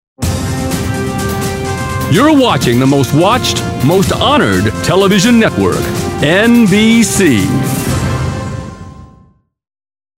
USA. All-American authority and highly versatile characters. 'Toon Pro.